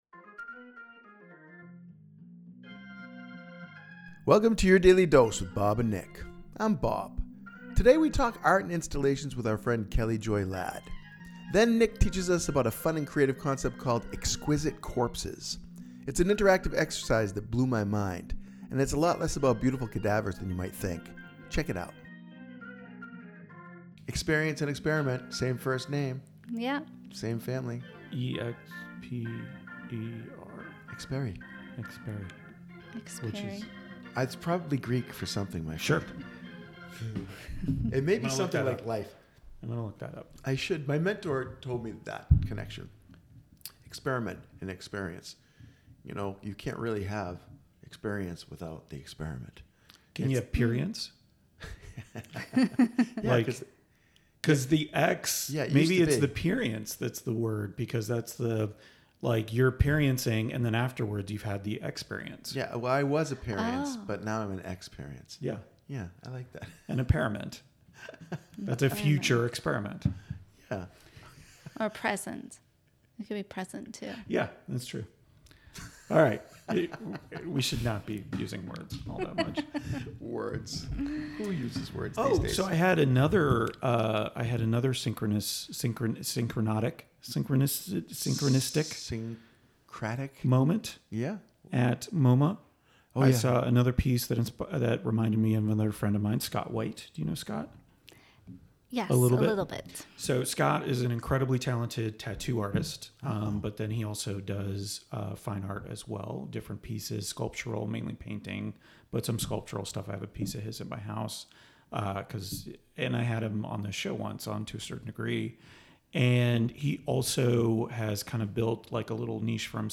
Good conversations, five days a week
Genres: Business, Comedy, Improv